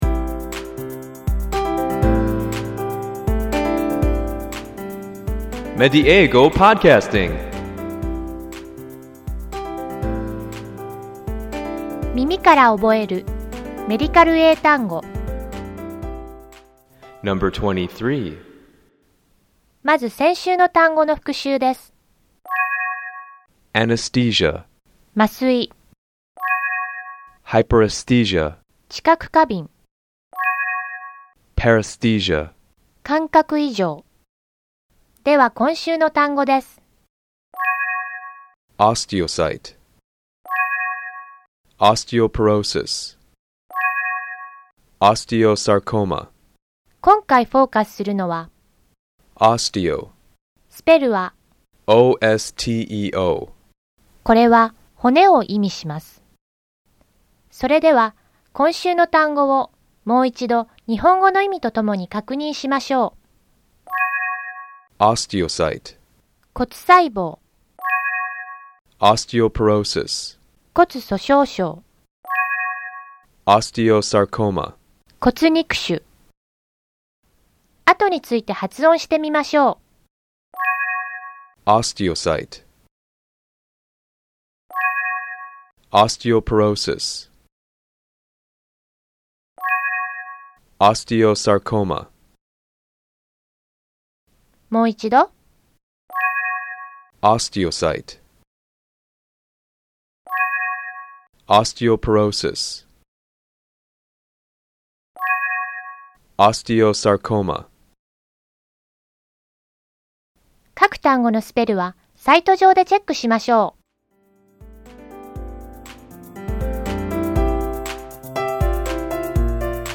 この「耳から覚えるメディカル英単語」では，同じ語源を持つ単語を毎週3つずつ紹介していきます。ネイティブの発音を聞いて，何度も声に出して覚えましょう。